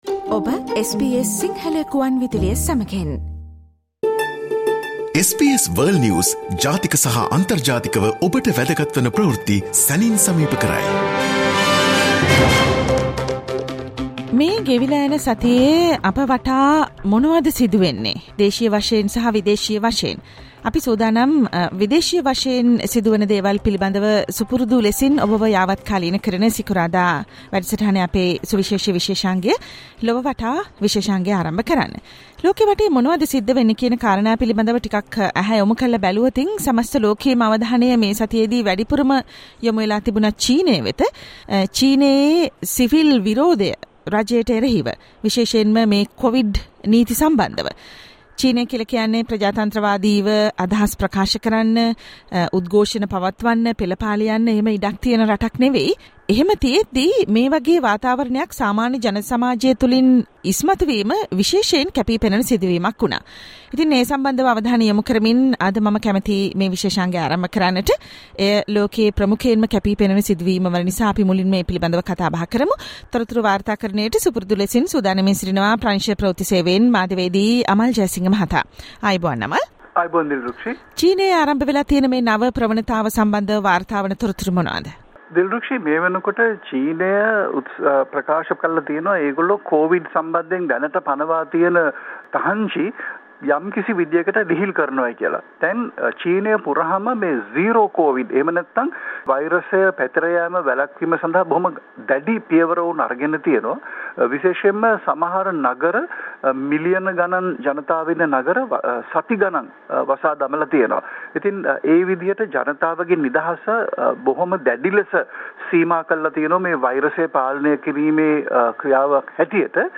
SBS Sinhala Radio weekly world News wrap